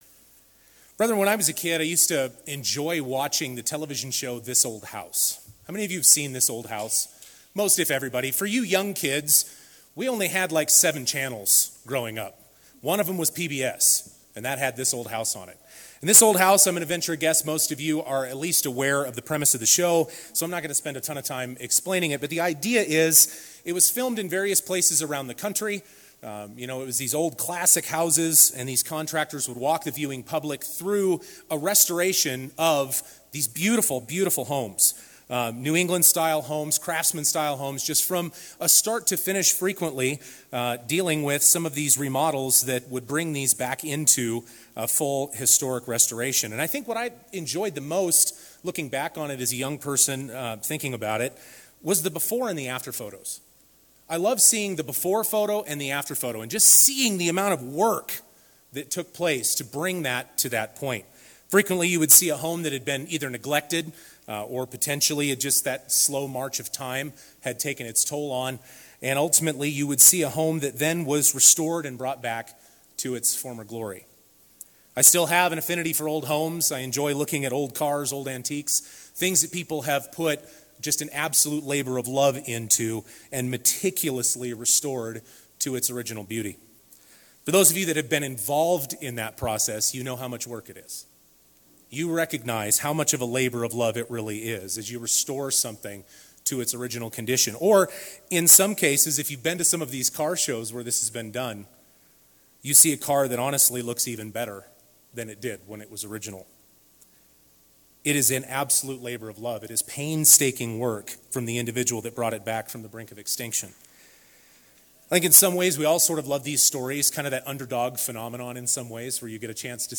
This sermon was given at the Estes Park, Colorado 2023 Feast site.